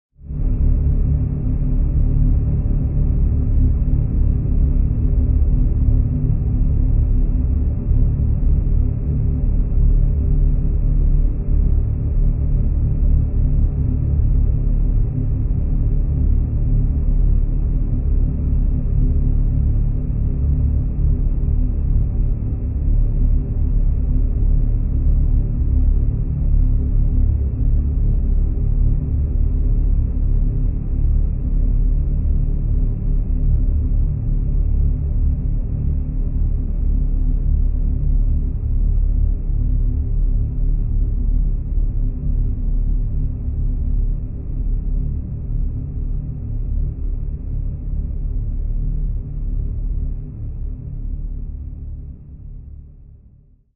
На этой странице собраны звуки черной дыры, преобразованные из электромагнитных и гравитационных волн.